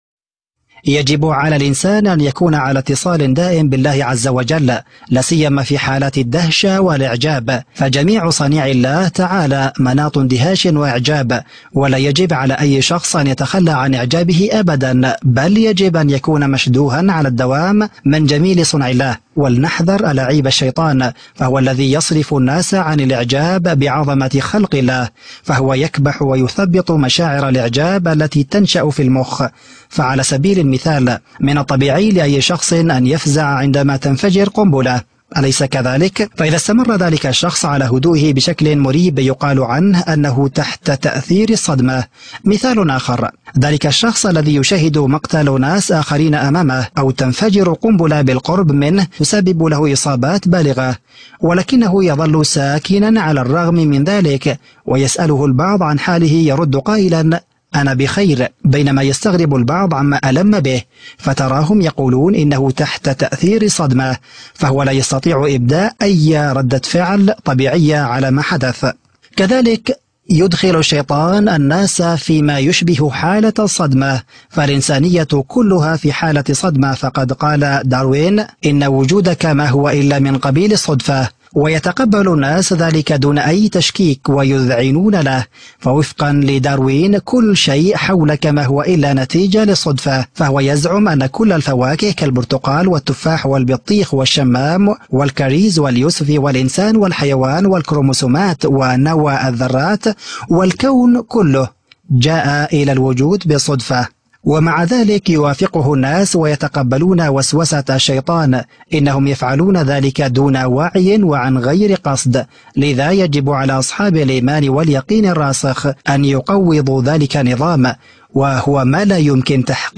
مقتطفات من حوار مباشر للسيد عدنان أوكتار على قناة A9TV المُذاع في 28 أغسطس/ آب 2016 عدنان أوكتار: يجب على الإنسان أن يكون على اتصال دائم بالله...